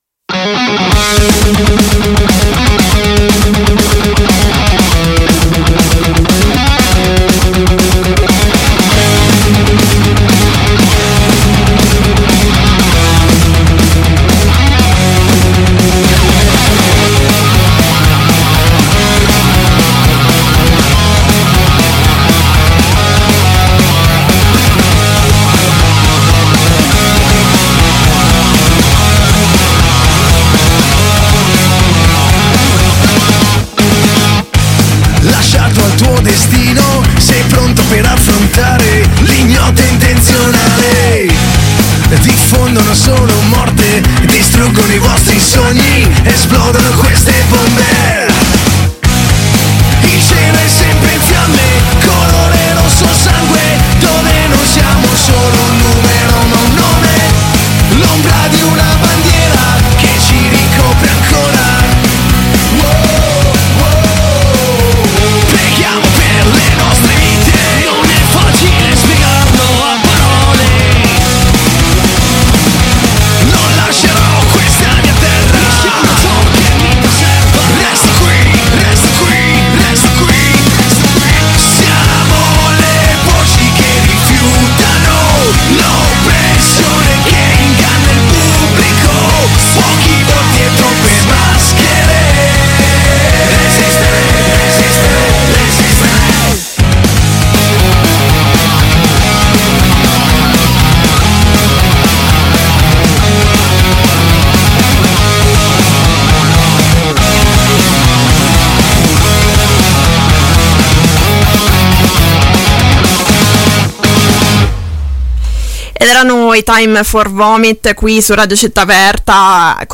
T.F.V. – L’ombra di una bandiera INTERVISTA T.F.V. T.F.V. – America